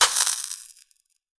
fireball_impact_sizzle_burn3.wav